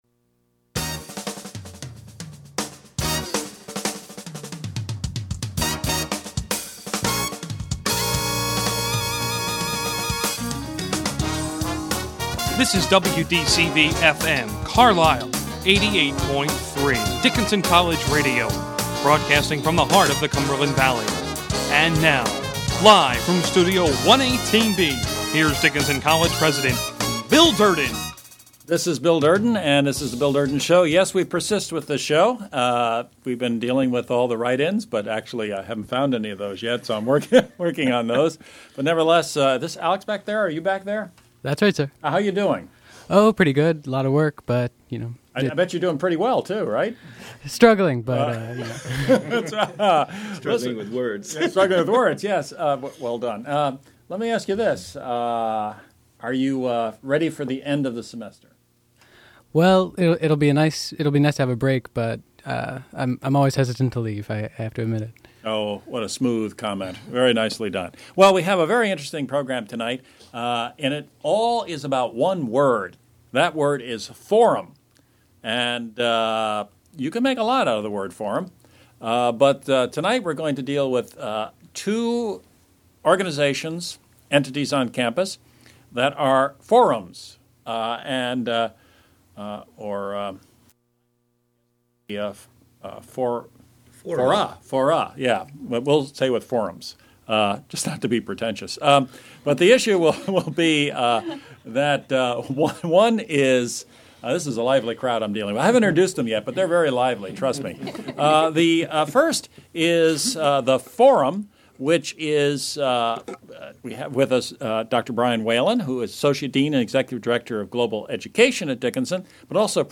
A radio show that focuses on the two forums at Dickinson College; The Clarke Forum for Contemporary Issues and The Forum on Education Abroad. download mp3 audio